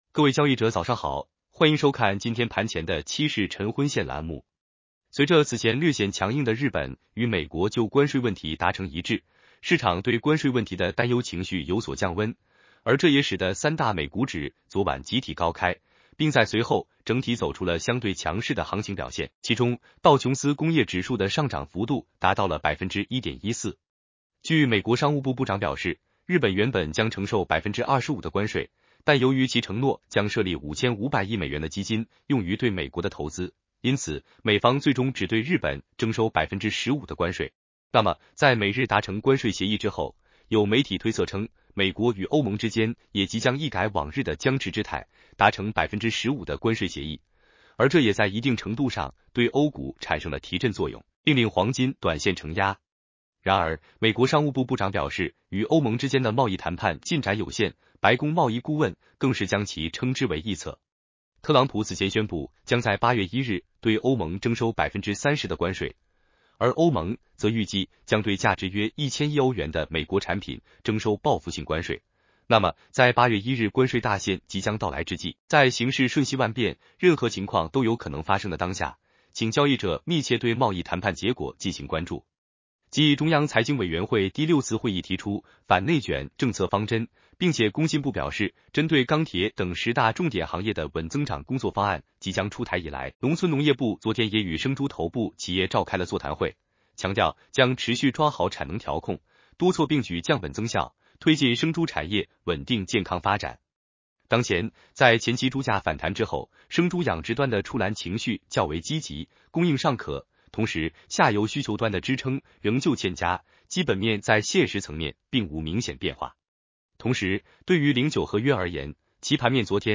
男生普通话版 下载mp3